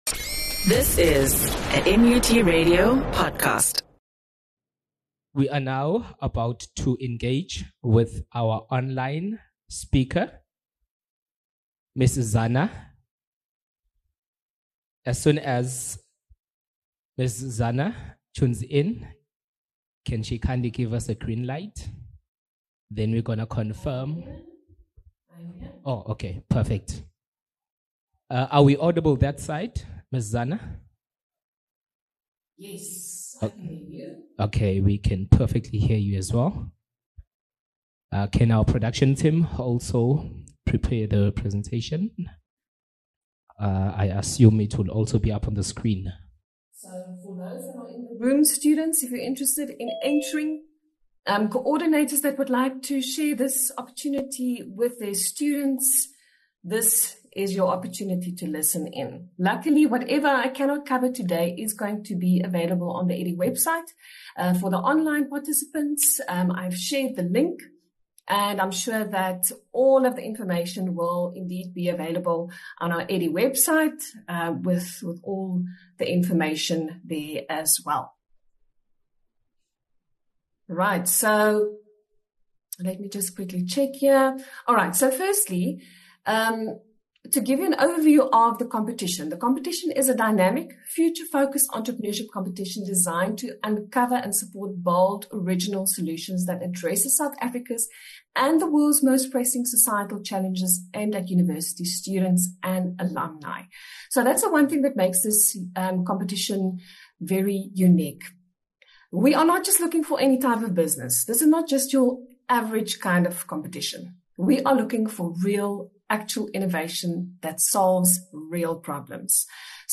The EDHE Absa Innovation Challenge, a national entrepreneurship competition, will be officially launched at MUT. The initiative, a partnership between Universities South Africa (USAf) and Absa Bank, aims to empower students and recent graduates from South Africa's 26 public universities to develop innovative, impactful, and scalable solutions addressing societal challenges.